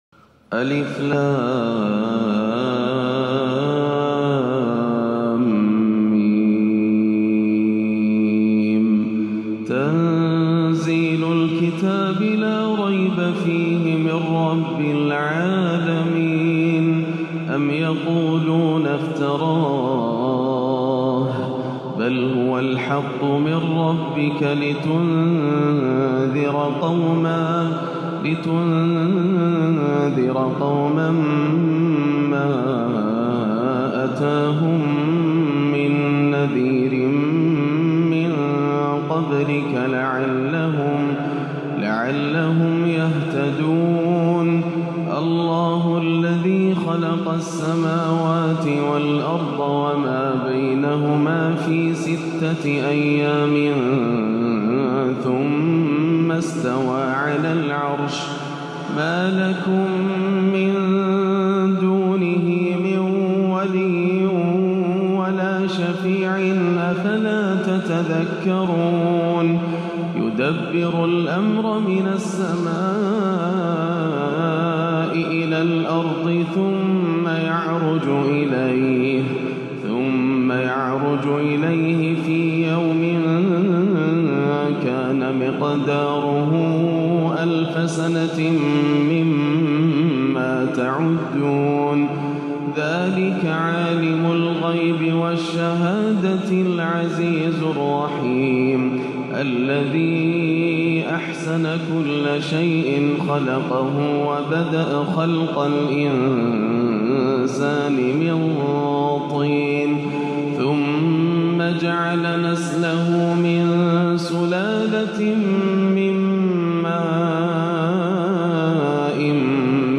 فجرية متنوعة المقامات تغنى بها شيخنا بصوته الحزين المؤثر لقرآن الفجر "السجدة والإنسان" - الجمعة 4-2-1438 > عام 1438 > الفروض - تلاوات ياسر الدوسري